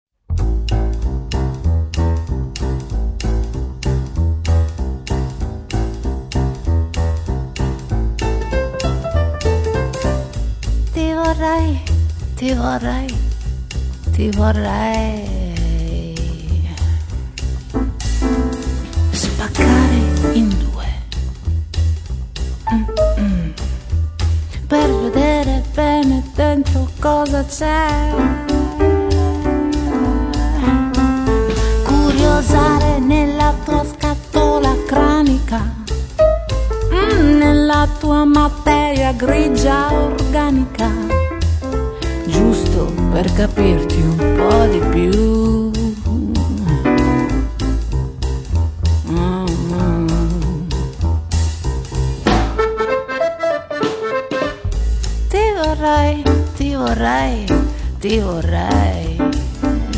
voce, piano,fisarmonica, tastiere
contrabbasso
batteria e percussioni
C'è molta teatralità nel modo di cantare